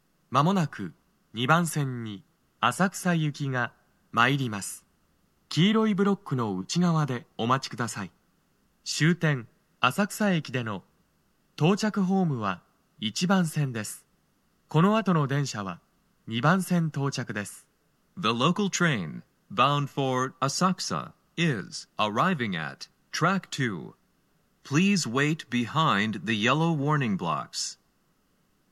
スピーカー種類 TOA天井型
鳴動は、やや遅めです。
接近放送 【男声